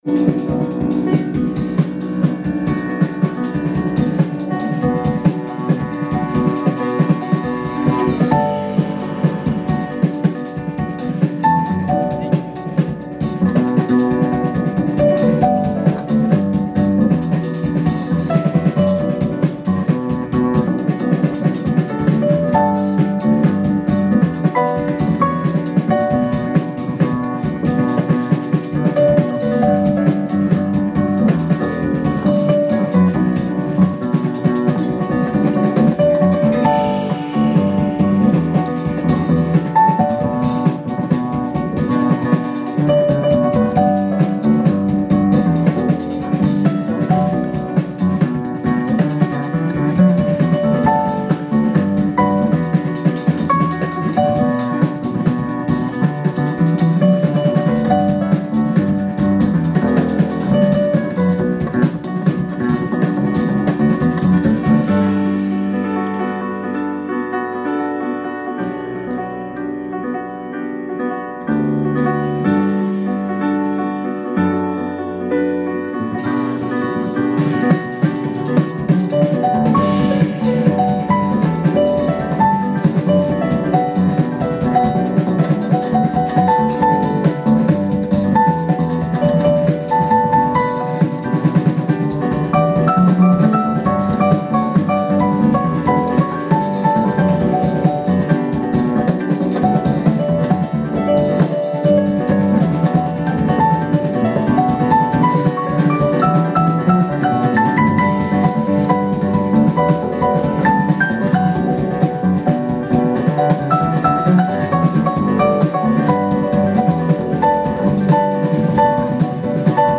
24th April 2016, The Hamilton, Washington DC